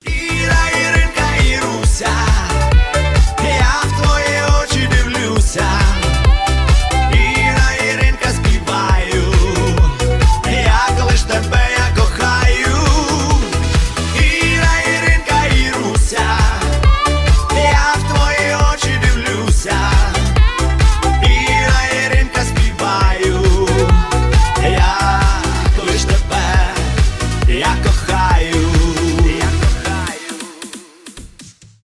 эстрадные